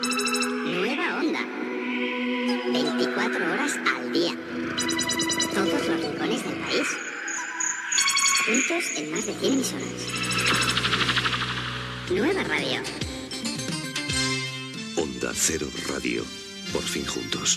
Indicatiu de la nova cadena de ràdio